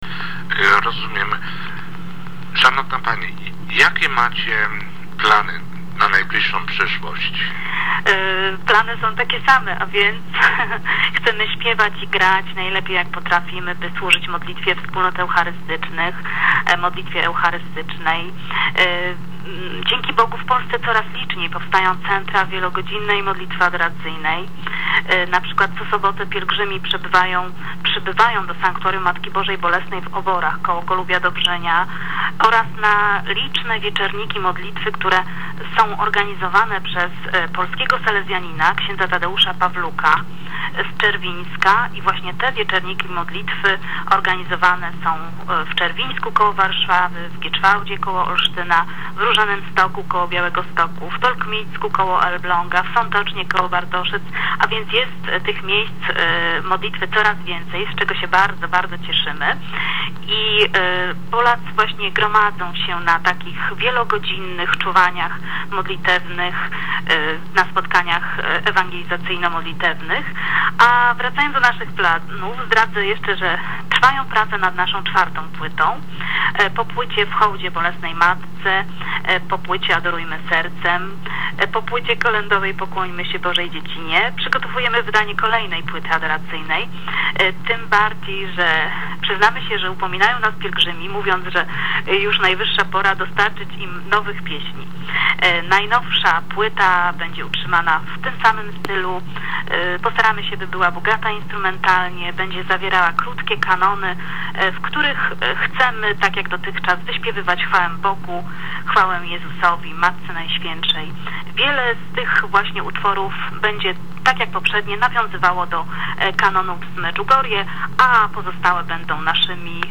Wywiad dla Polonii – Radio Pomost 20.03.2008 cz. 2